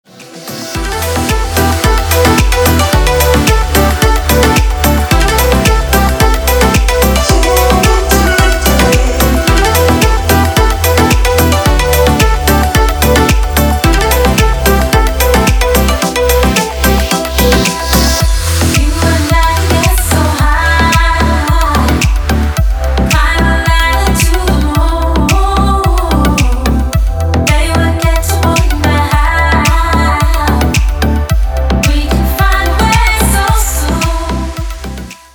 • Качество: 320, Stereo
dance
Electronic
без слов
tropical house